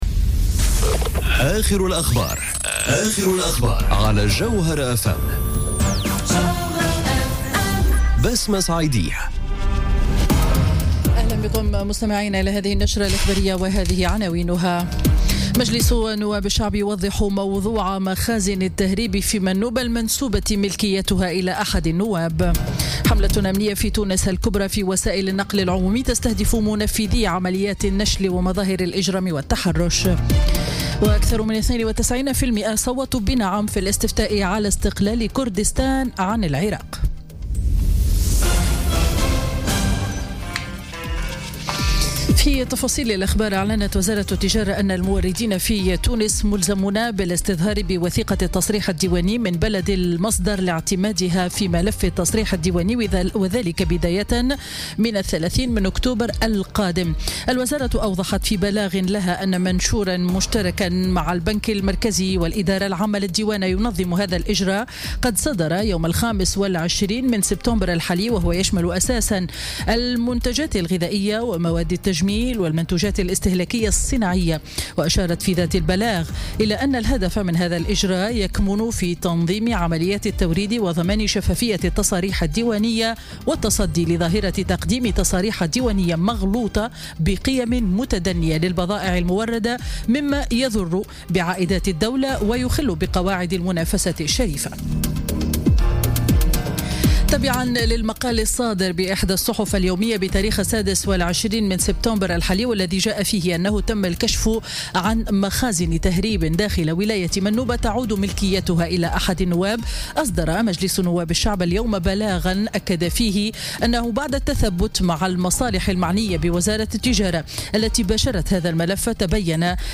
نشرة أخبار السابعة مساء ليوم الأربعاء 27 سبتمبر 2017